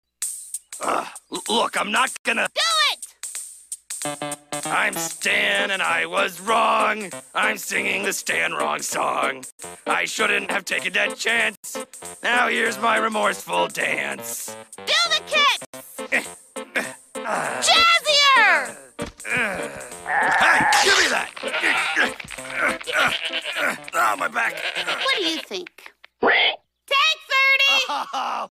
2. Стэн танцует и поет